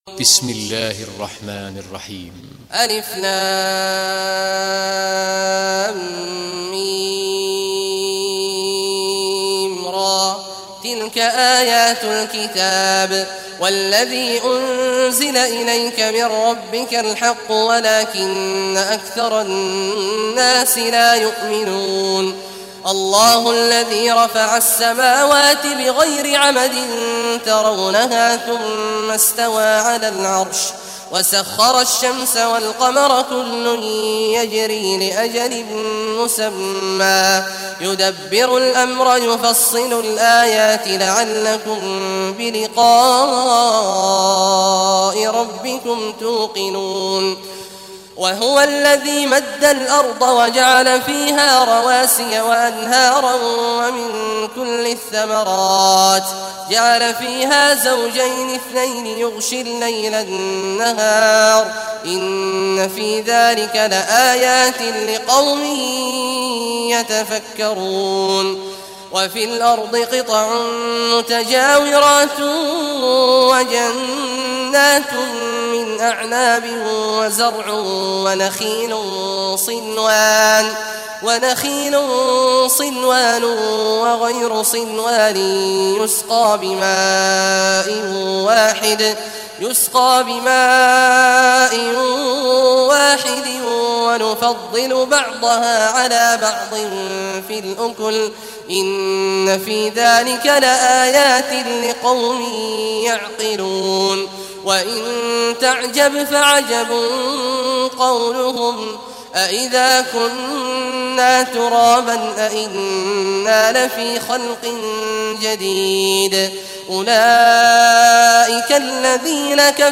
Surah Raad Recitation by Sheikh Awad al Juhany
Surah Raad, listen or play online mp3 tilawat / recitation in Arabic in the beautiful voice of Sheikh Abdullah Awad al Juhany.